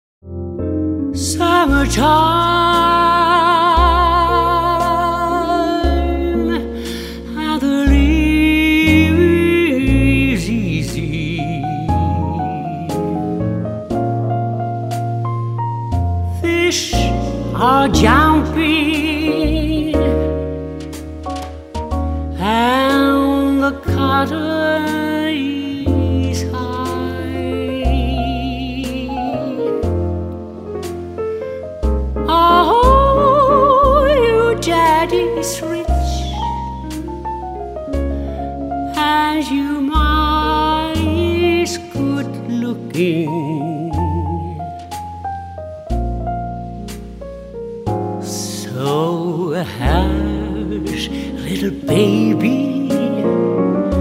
Balladen,  Jazz und Swing
mit ihrer tiefen Kontra-Alt Stimme
Orchesterbegleitung: Combo oder Big Band